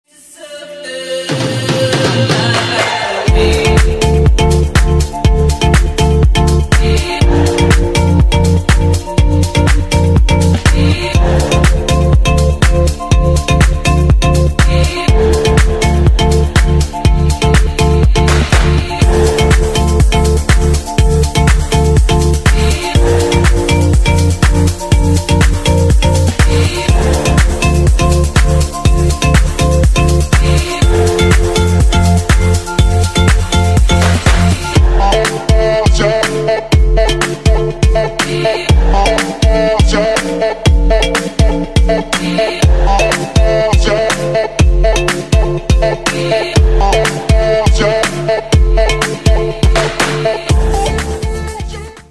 • Качество: 128, Stereo
tropical house
Красивая музыка в необычном стиле Tropical house